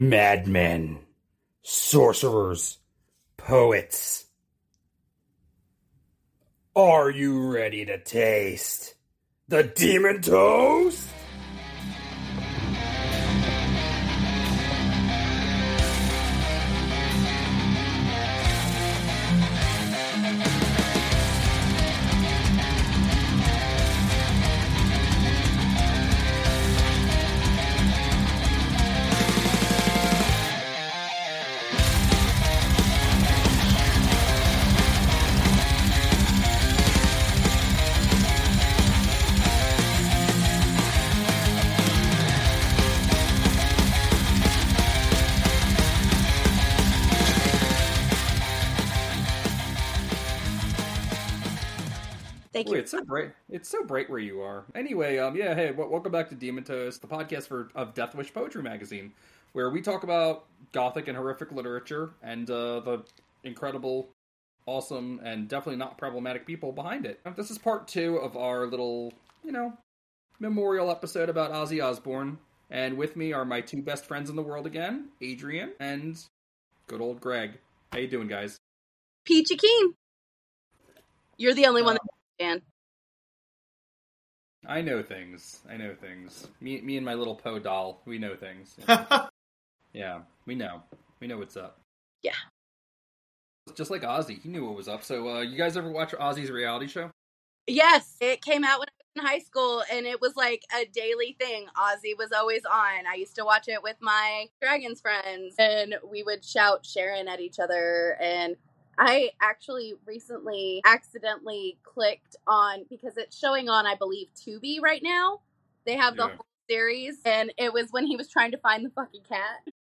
our host is a great singer)